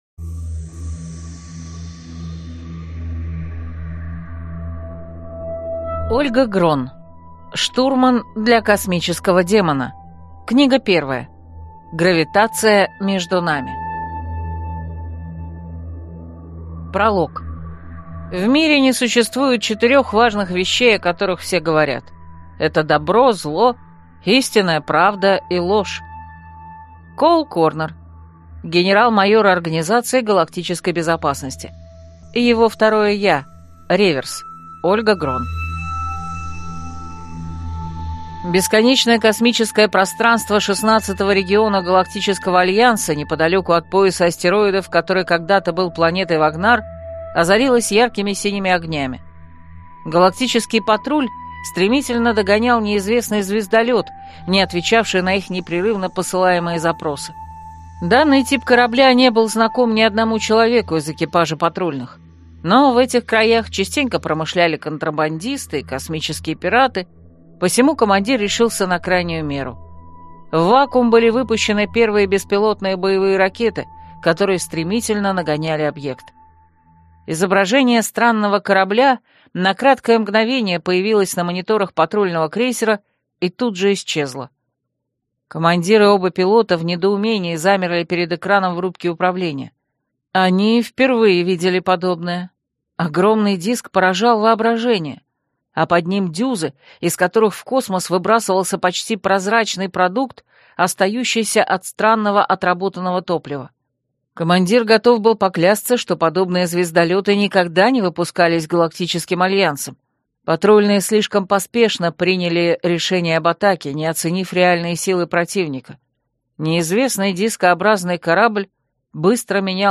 Аудиокнига Штурман для космического демона. Гравитация между нами | Библиотека аудиокниг